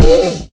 Minecraft Version Minecraft Version latest Latest Release | Latest Snapshot latest / assets / minecraft / sounds / mob / horse / zombie / hit2.ogg Compare With Compare With Latest Release | Latest Snapshot